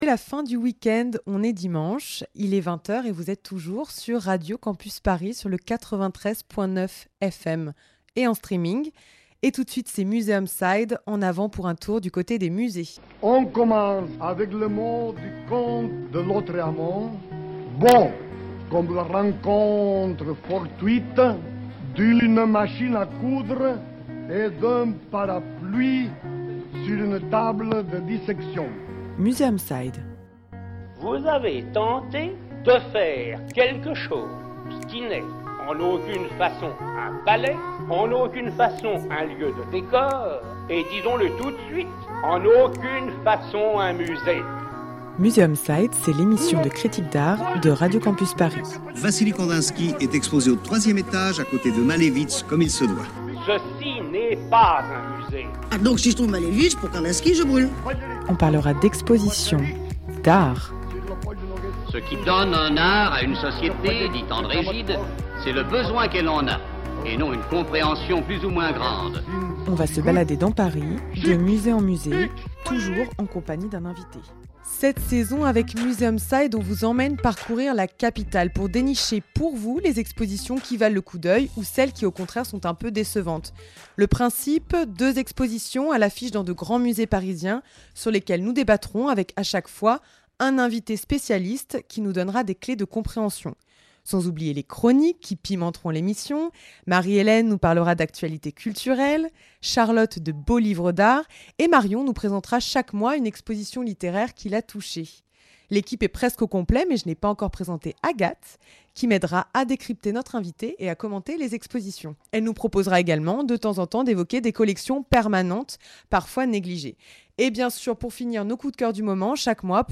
Cette saison, avec Museum side, on vous emmène parcourir la capitale pour dénicher pour vous les expositions qui valent le coup d’œil ou celles qui au contraire sont un peu décevantes. Le principe : deux expositions à l'affiche dans de grands musées parisiens sur lesquelles nous débattrons avec à chaque fois un invité spécialiste qui nous donnera des clés de compréhension.